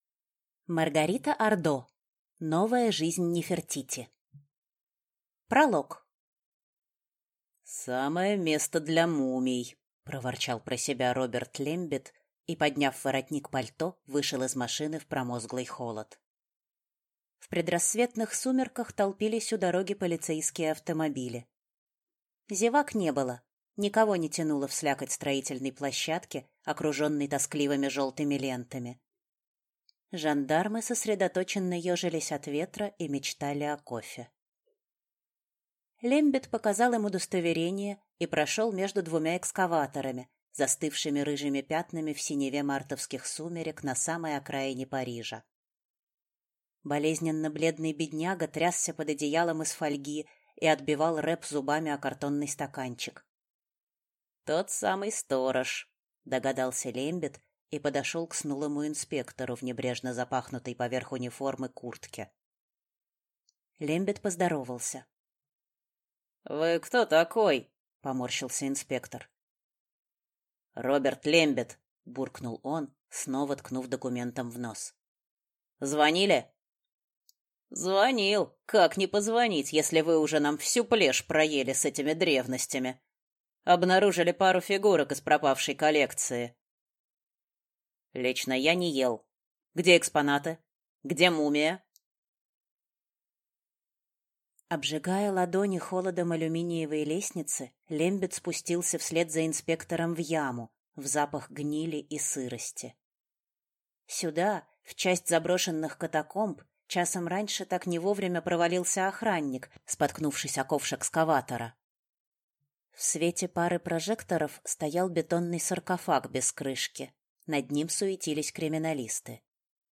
Аудиокнига Новая жизнь Нефертити | Библиотека аудиокниг